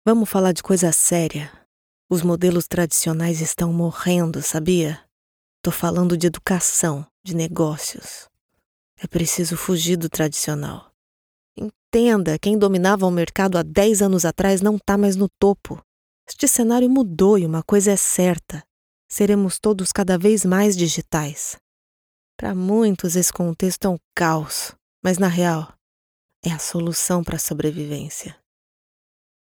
Sprechprobe: Industrie (Muttersprache):
My voice style is natural and conversational, with a neutral accent. My voice is very warm, versatile, conveys credibility, in addition to being jovial, expressive and extremely professional.